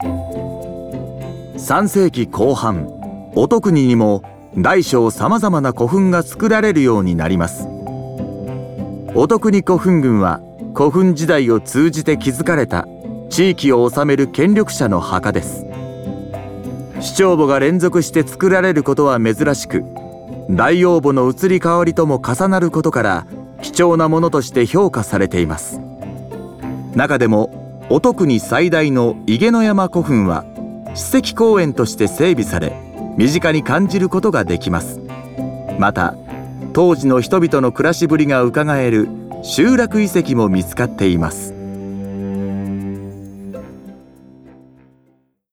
音声ガイド